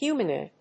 音節hu・man・oid 発音記号・読み方
/hjúːmən`ɔɪd(米国英語), ˈhju:mʌˌnɔɪd(英国英語)/